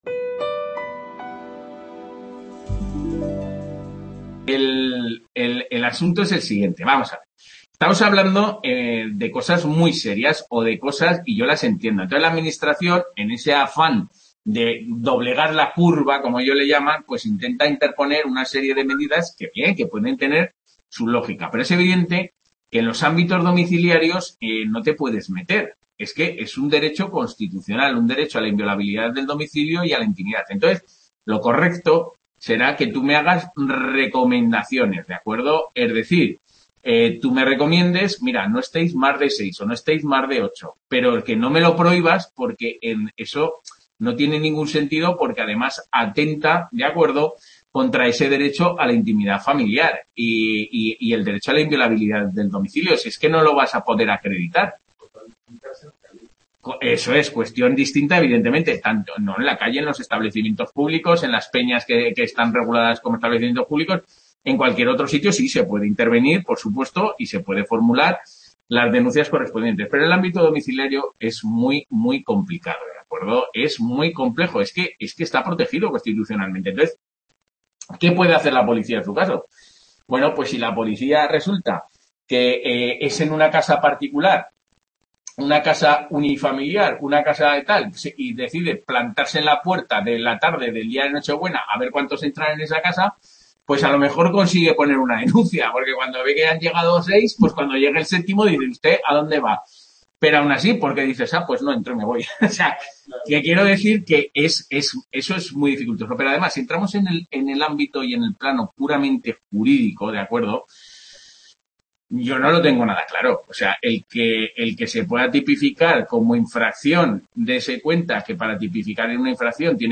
TUTORIA
Video Clase